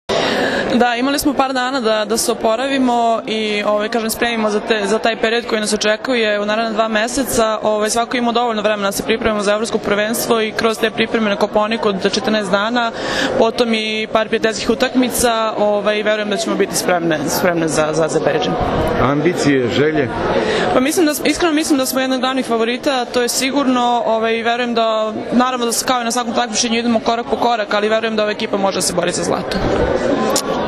IZJAVA MILENE RAŠIĆ